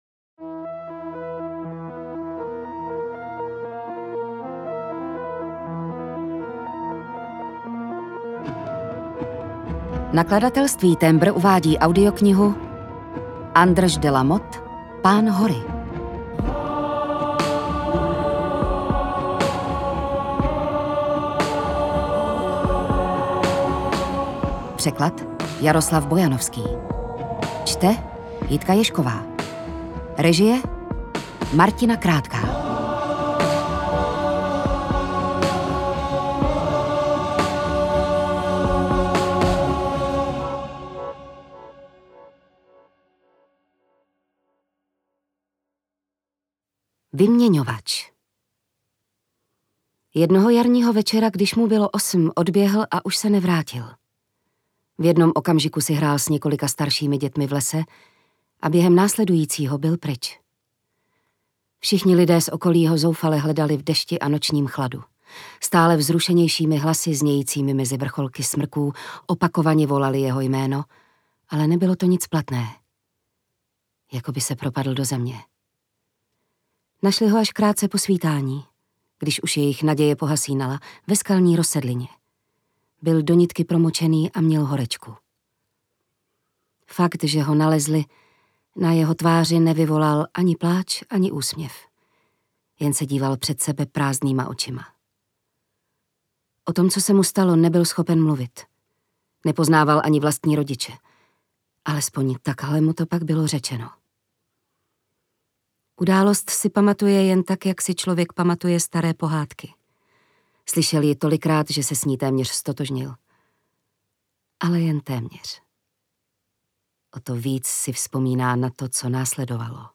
Pán hory audiokniha
Ukázka z knihy
• InterpretJitka Ježková